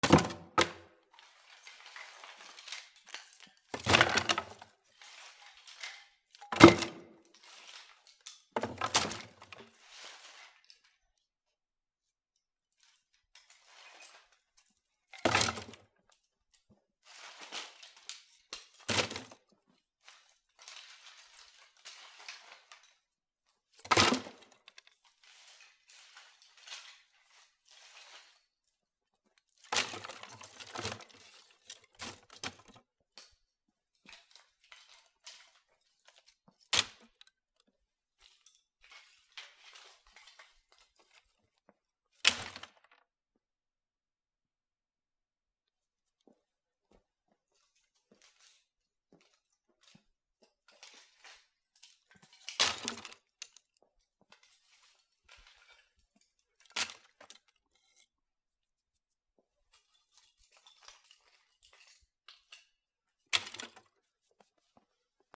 / M｜他分類 / L01 ｜小道具 /
木のごみを箱に入れる
『ゴトン』